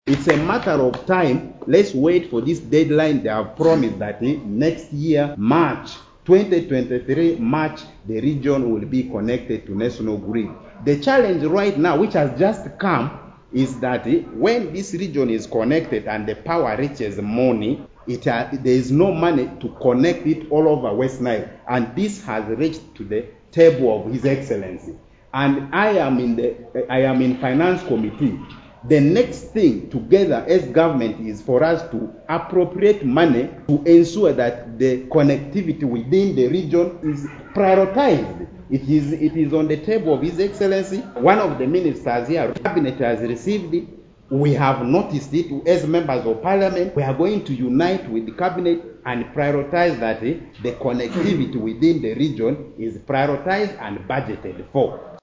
The area Member of Parliament for Arua central - Hon Jackson Atima Lee